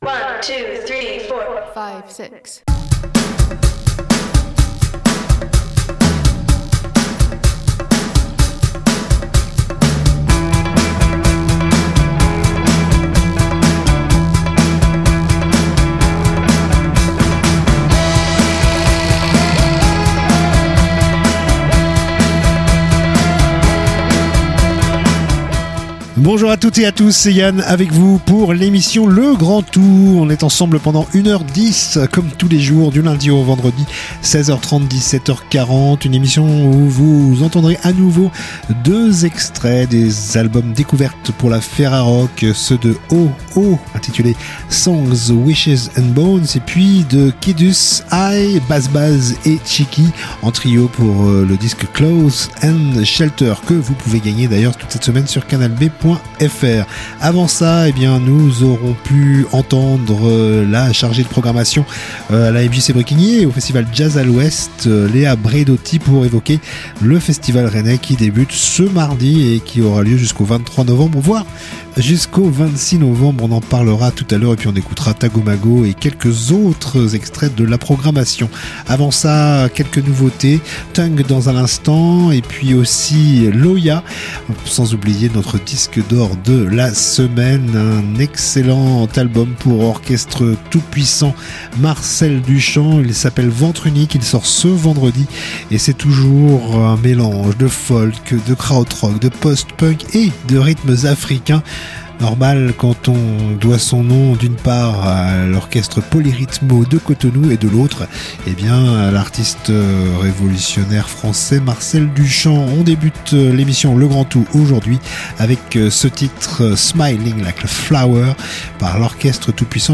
itv + infos-concerts